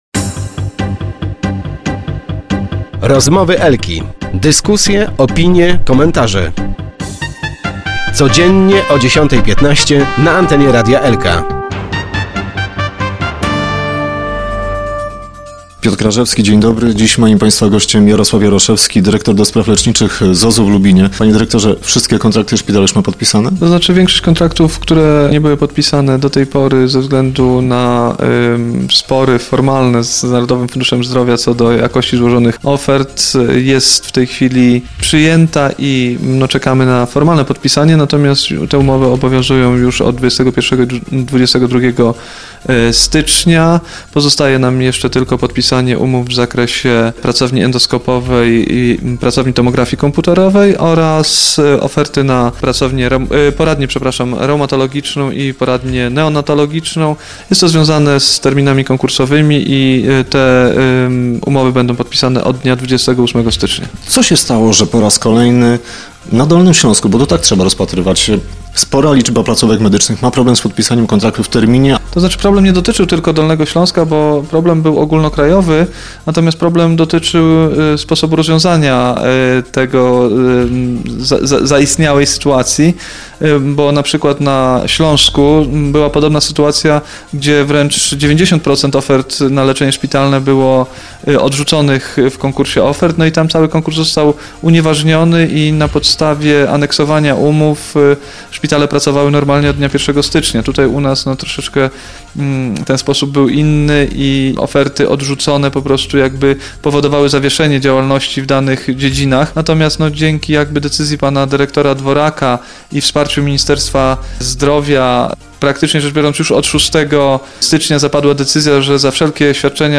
w lubińskim studiu Radia Elka